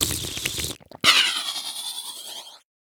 drink.wav